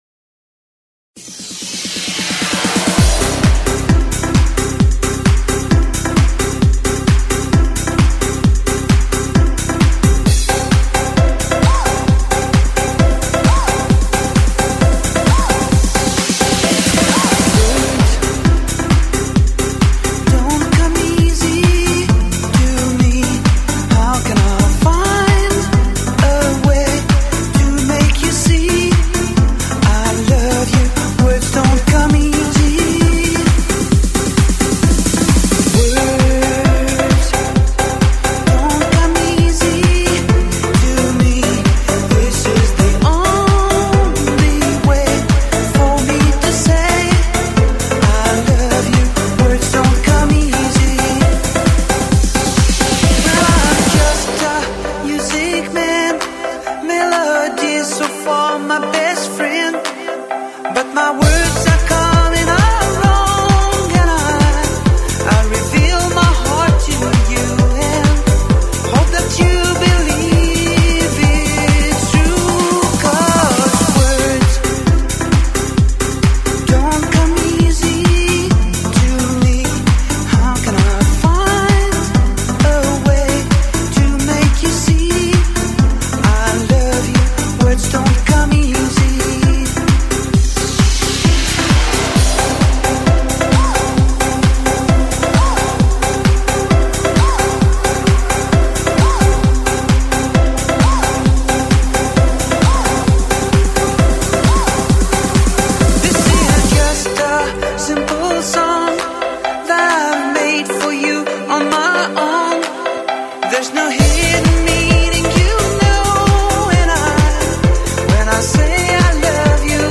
Главная » Файлы » Танцы 80 | 80 Danse » Разное